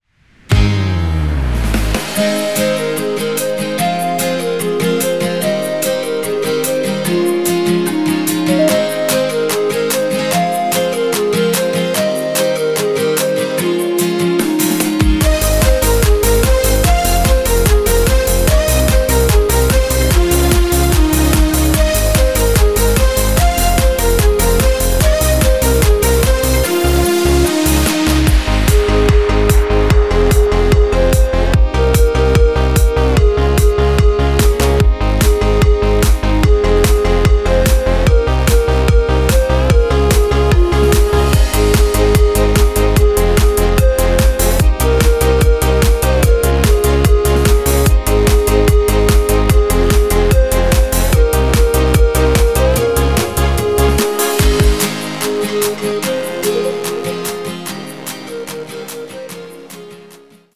Audio Recordings based on Midi-files
Our Suggestions, Pop, German, 2020s